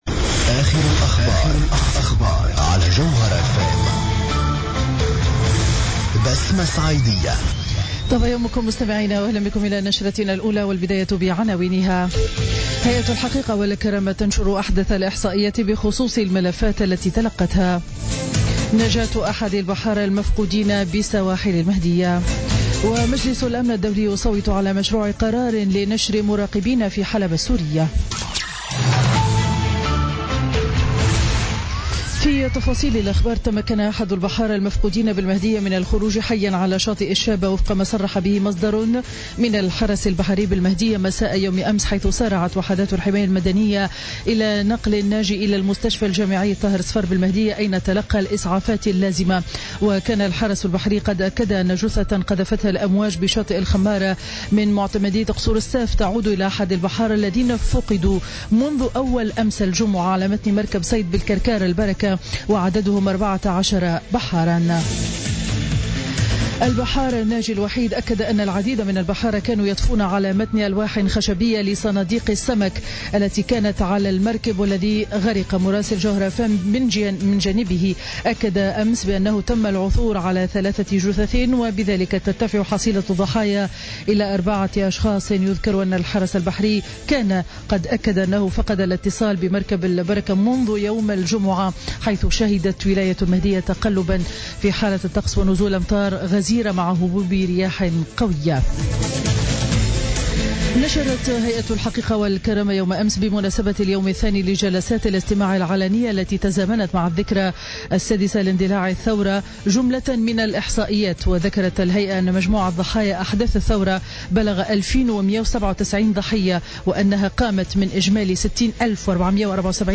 نشرة أخبار السابعة صباحا ليوم الأحد 18 ديسمبر 2016